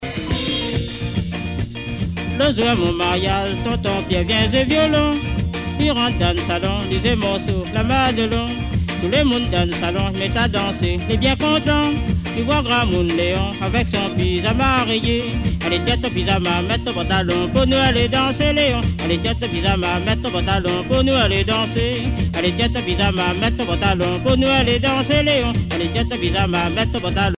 danse : séga
Genre strophique
Pièce musicale éditée